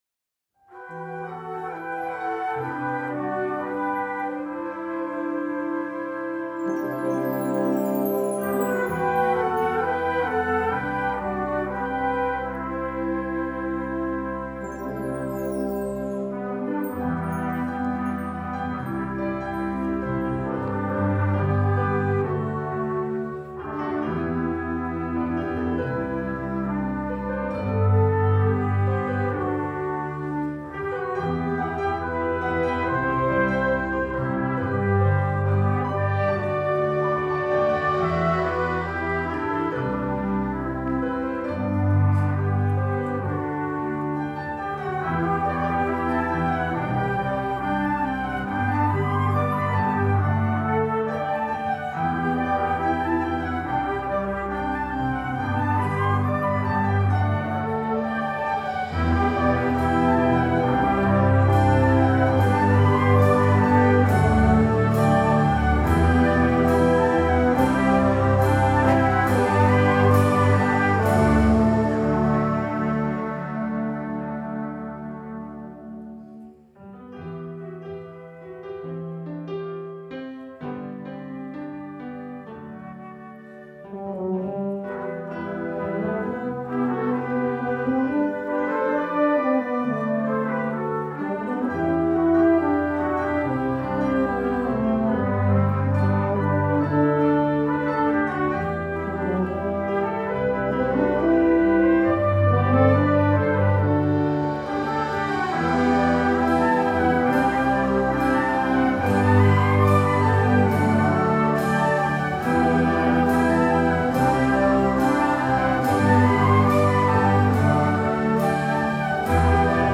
Die Musikgesellschaft St. Moritz (gegründet 1876) ist ein Dorfverein mit ca. 50 Musikantinnen und Musikanten.
Der Besetzungstyp ist Harmonie.